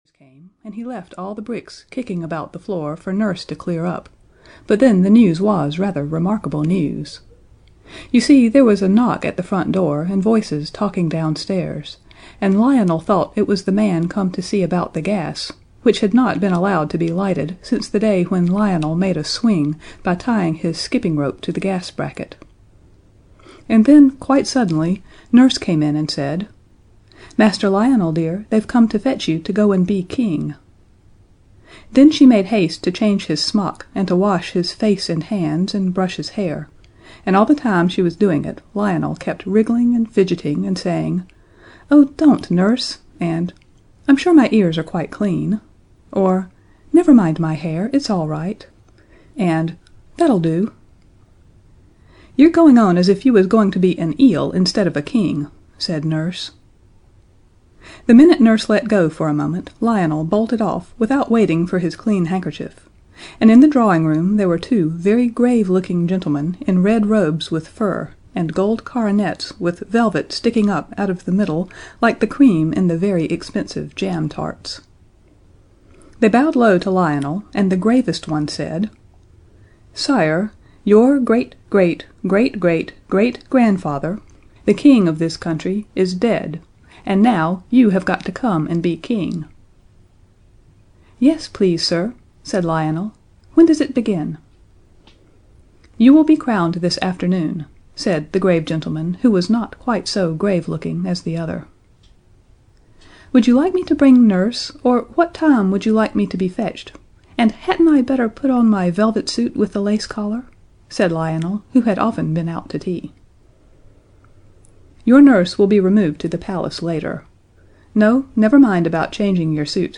The Book of Dragons (EN) audiokniha
Ukázka z knihy